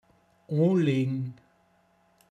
Pinzgauer Mundart Lexikon
Details zum Wort: o(n)legn. Mundart Begriff für anziehen